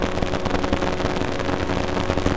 ELECTRICITY_Fuzzy_Spikey_loop_mono.wav